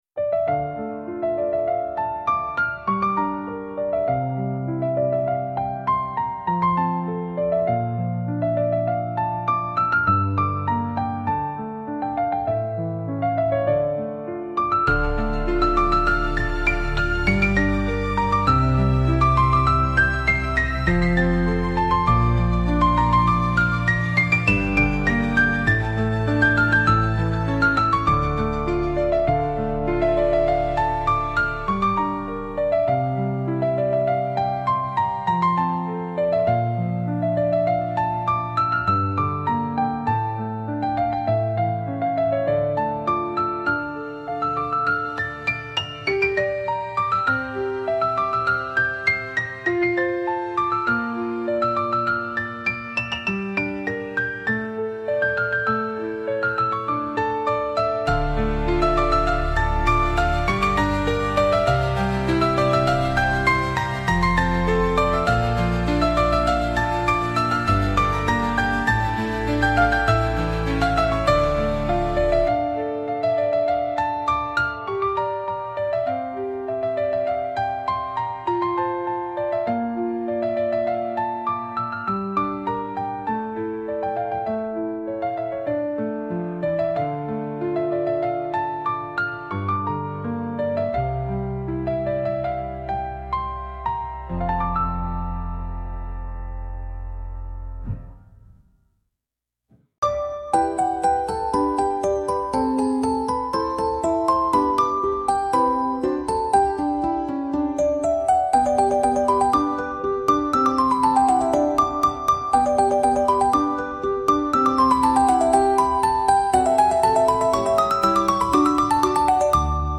Scopri la ninna nanna ispirata a Mozart, una melodia dolce di 30 minuti per cullare il tuo bambino in un abbraccio di pace e amore.
Il pianoforte sussurra, gli archi avvolgono, un flauto lieve accarezza i pensieri. La musica dura 30 minuti, con un ritmo lento e cullante, perfetto per rilassare il corpo e rassicurare il cuore.